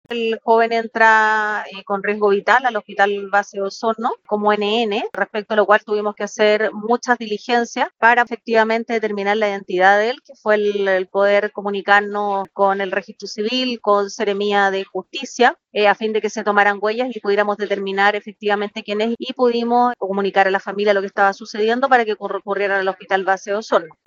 La víctima fue identificada solo las últimas horas, a través de gestiones entre las unidades investigativas y el Registro Civil, dado que el joven no portaba documentos que ayudaran a dilucidar su identidad. Así fue relatado por la fiscal jefe de Osorno, María Angélica de Miguel.